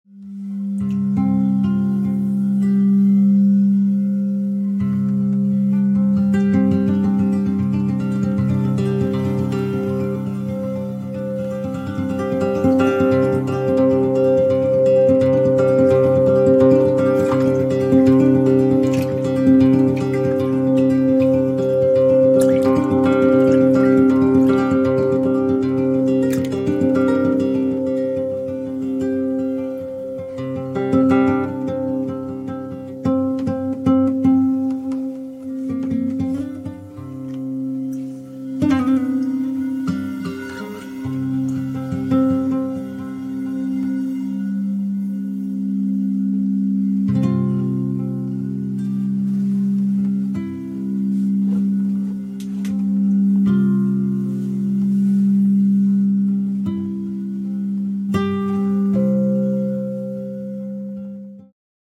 קערות הקריסטל במים